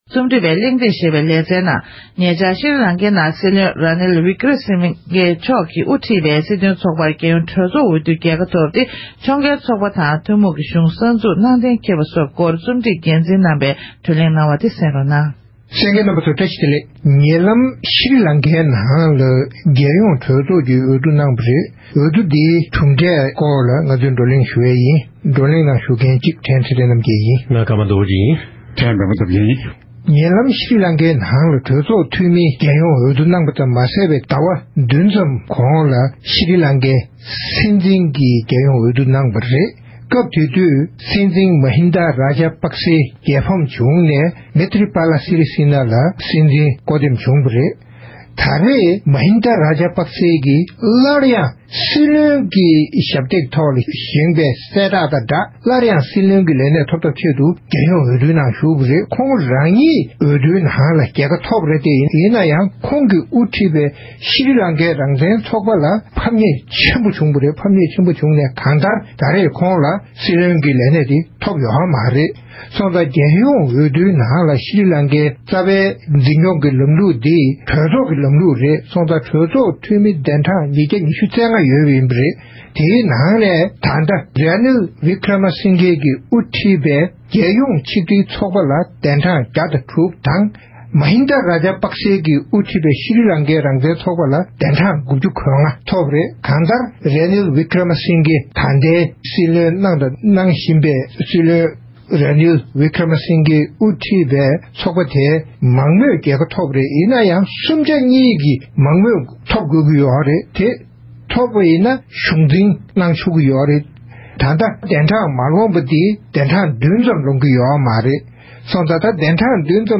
༄༅། །ཐེངས་འདིའི་རྩོམ་སྒྲིག་པའི་གླེང་སྟེགས་ཞུ་བའི་ལེ་ཚན་ནང་། ཤྲི་ལང་ཀའི་རྒྱལ་ཡོངས་གྲོས་ཚོགས་ཀྱི་འོས་བསྡུ་དང་འབྲེལ་བའི་སྐོར་ལ་འདི་ག་རླུང་འཕྲིན་ཁང་གི་རྩིམ་སྒྲིག་པའི་དབར་གླེང་མོལ་ཞུས་པ་དེ་གསན་རོགས་གནང་།